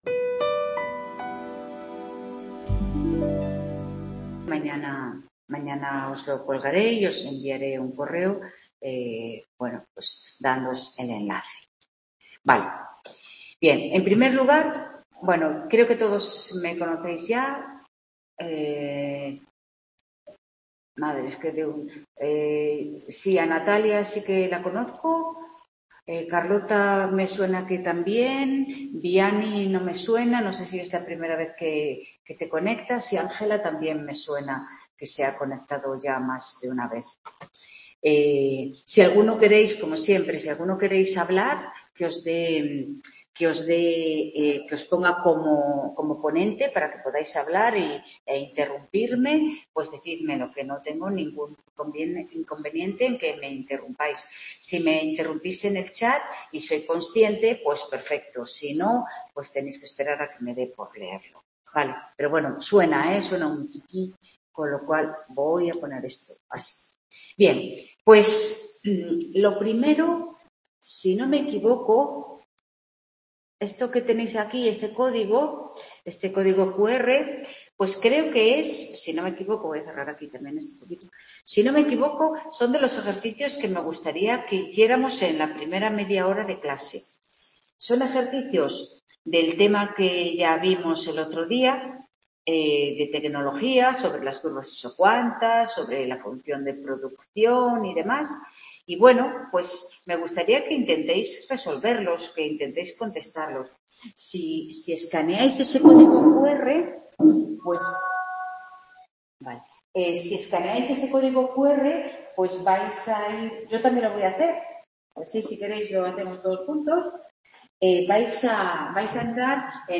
Tutoría Microeconomía 2º ADE 17-10-22 | Repositorio Digital
Se resolvieron en la primera parte de la tutoría una serie de ejercicios sobre el tema de tecnología (usando un cuestionario en Forms), La segunda parte se dedicó a introducir el tema de la función de costes. *Hubo algunos problemas de conexión durante la grabación de la tutoría, disculpas de antemano.